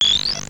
There are four different sounds produced by the game.
QSDefender_AlienDestroyed.wav